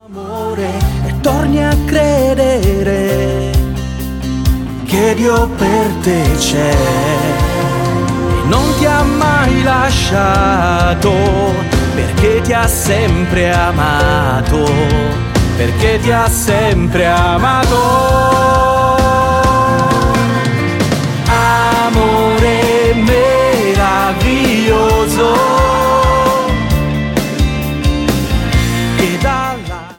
Il CD contiene i brani cantati e le basi musicali...